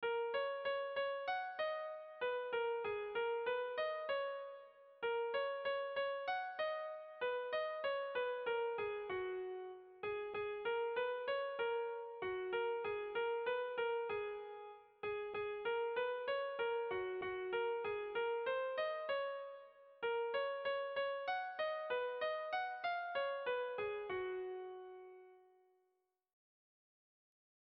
Hamarreko txikia (hg) / Bost puntuko txikia (ip)
A1A2B1B2A2